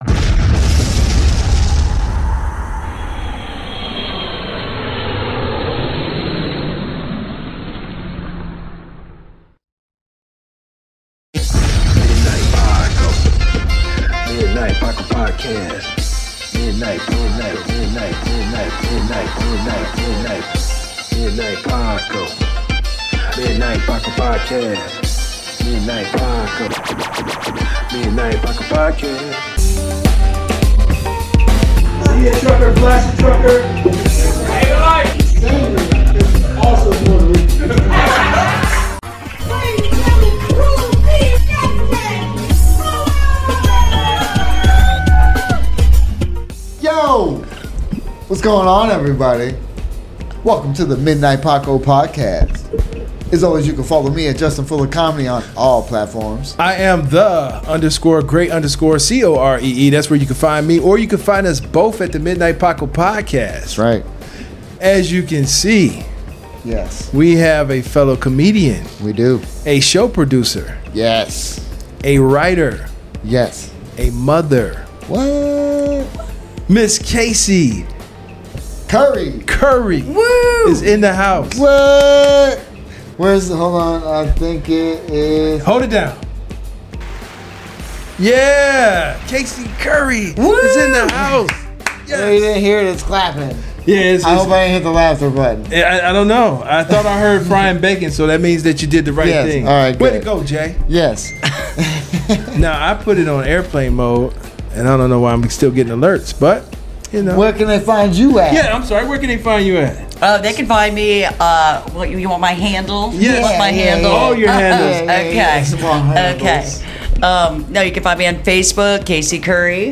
Get ready for an entertaining talk filled with laughter and insight into the world of comedy.